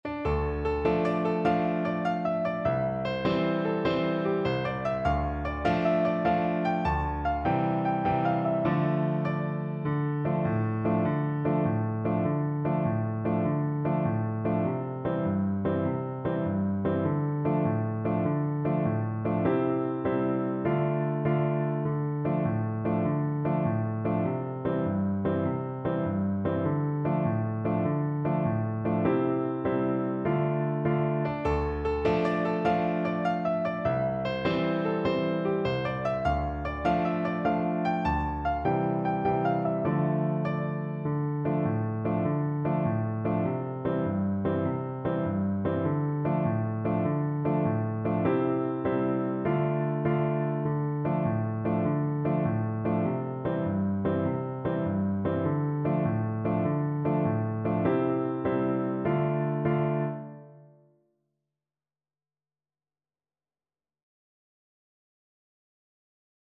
Allegro .=c.100 (View more music marked Allegro)
6/8 (View more 6/8 Music)
Traditional (View more Traditional Voice Music)